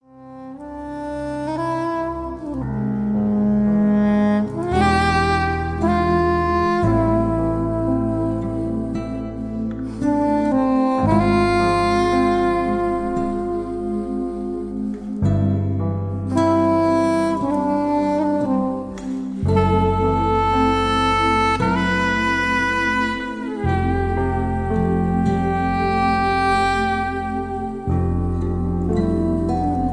Ballad.
Tenor and Soprano Saxophones/EWI
Bass
Keyboards
Drums
Guitar
Trumpet
Percussion